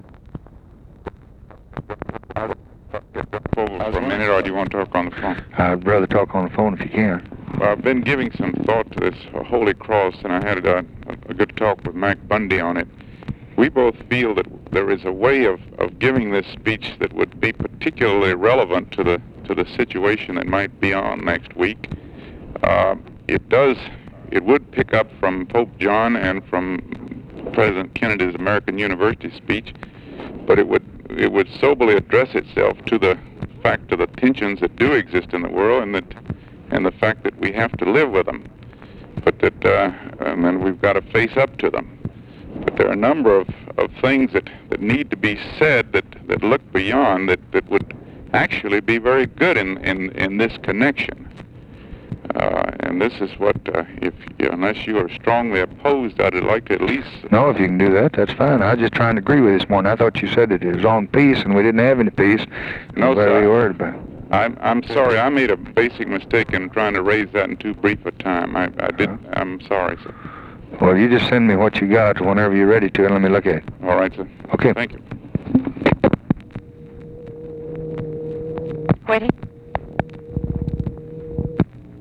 Conversation with DOUGLASS CATER, June 6, 1964
Secret White House Tapes